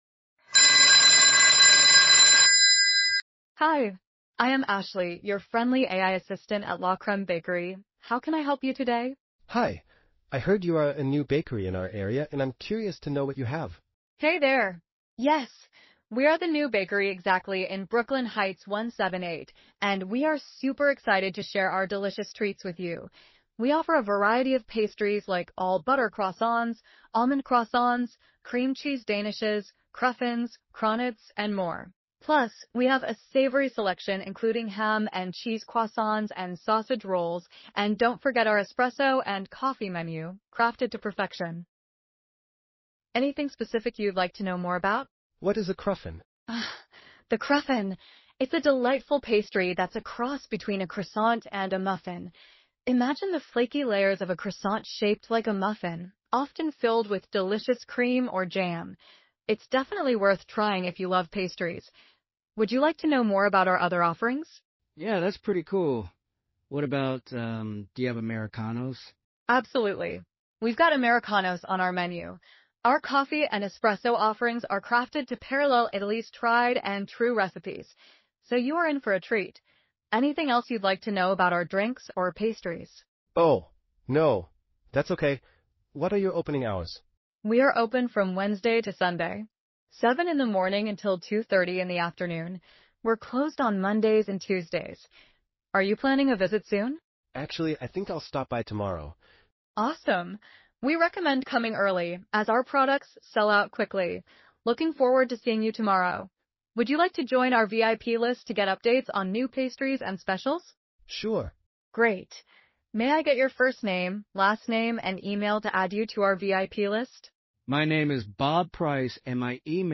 Escuche a continuación una demostración de voz AI personalizada
Recepcionista de Inteligencia Artificial